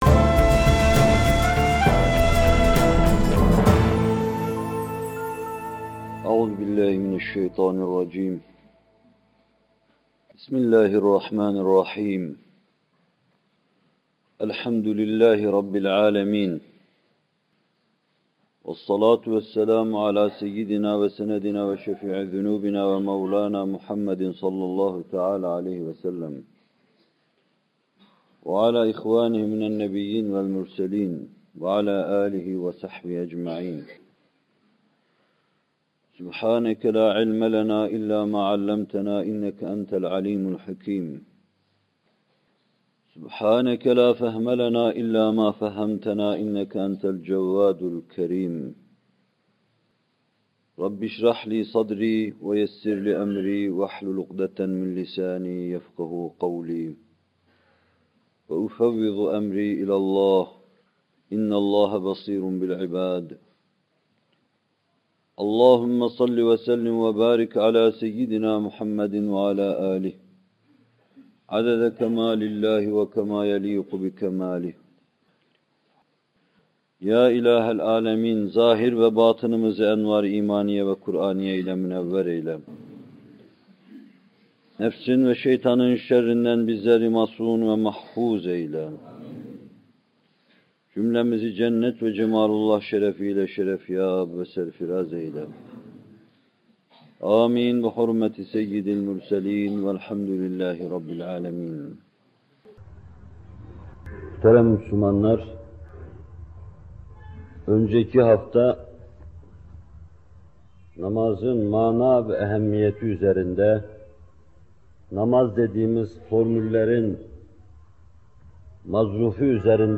Bu bölüm Muhterem Fethullah Gülen Hocaefendi’nin 15 Eylül 1978 tarihinde Bornova/İZMİR’de vermiş olduğu “Namaz Vaazları 5” isimli vaazından alınmıştır.